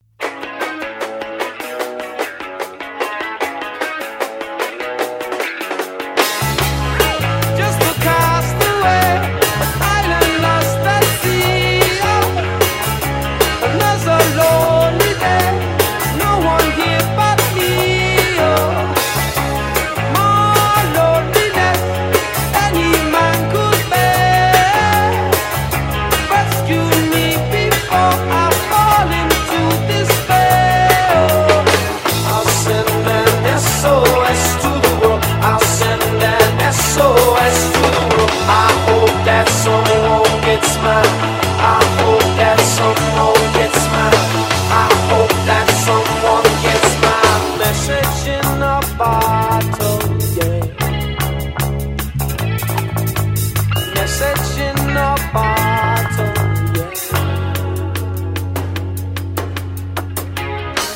Just got some flatwounds too!
That sounded really good.